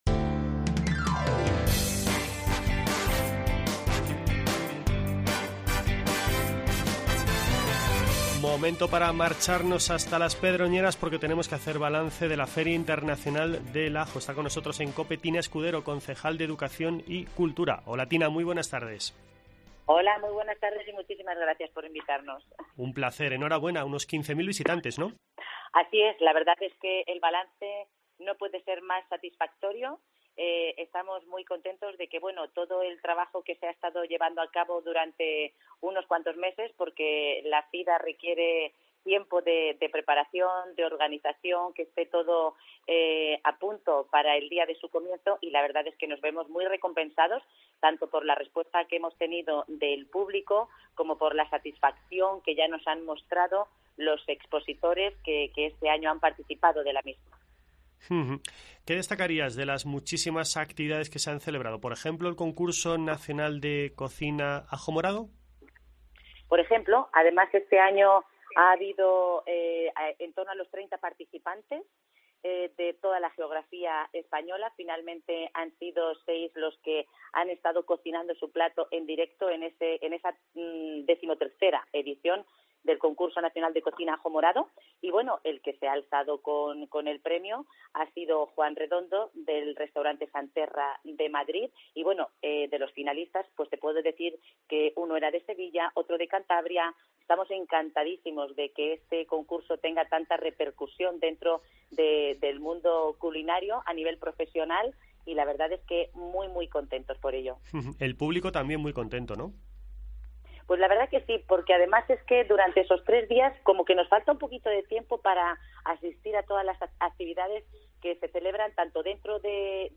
Entrevista con Tina Escudero, concejal de Educación y Cultura